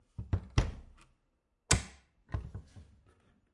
随机的 " 木门柜打开关闭3
描述：门木柜打开close3.flac